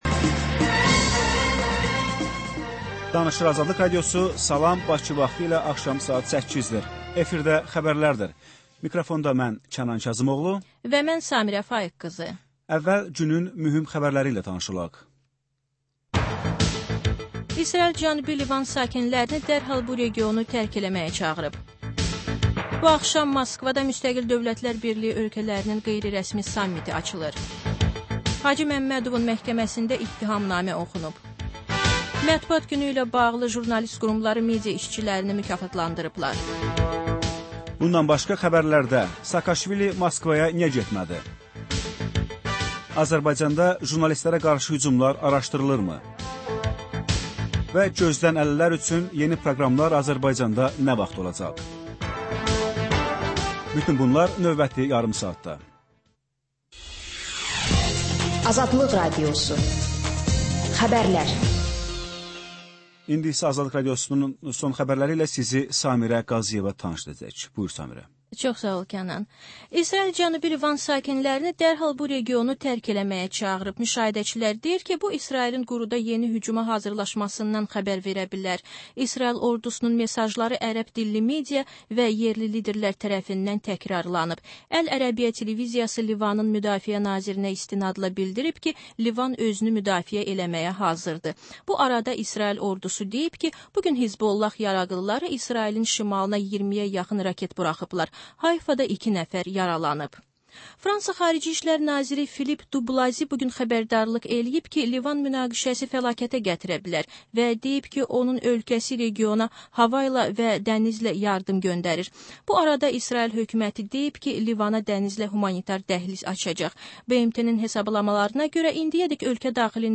Xəbər, reportaj, müsahibə. Sonra: Günün Söhbəti: Aktual mövzu barədə canlı dəyirmi masa söhbəti.